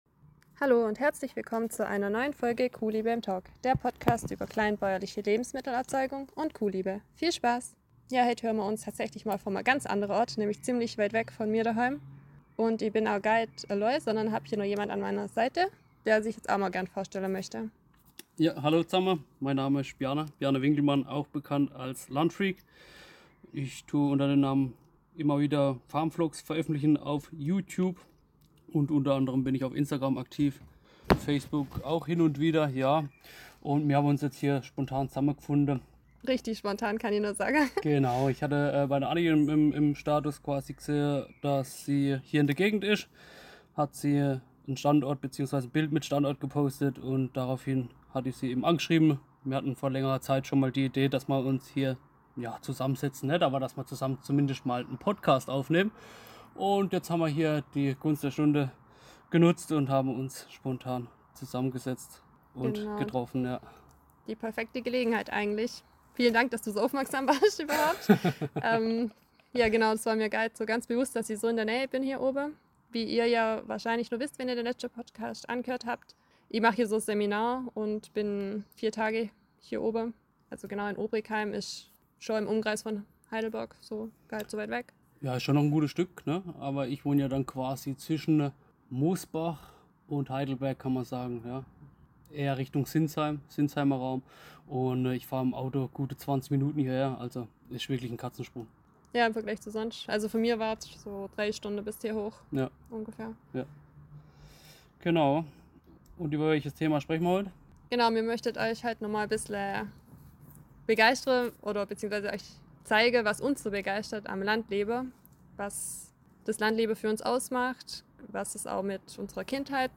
Ohne Skript oder Notizen haben wir einfach drauf losgeredet und unsere Meinung mit euch geteilt.